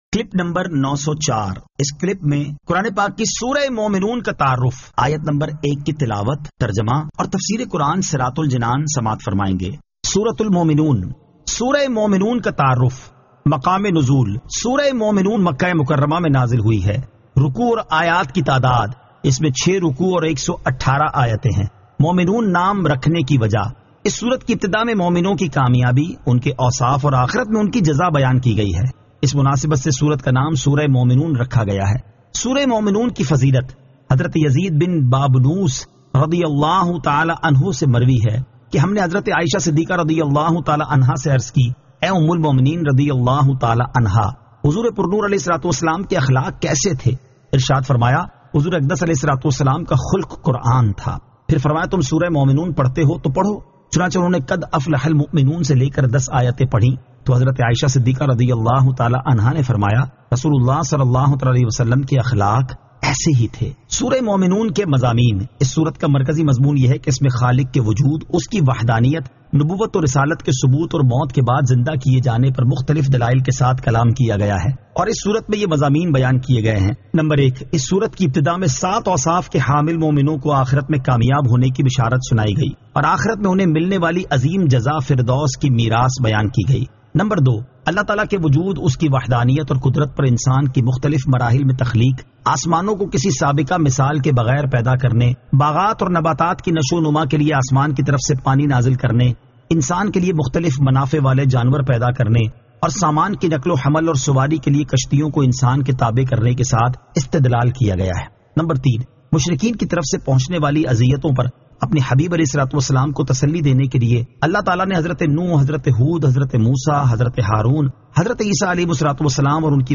Surah Al-Mu'minun 01 To 01 Tilawat , Tarjama , Tafseer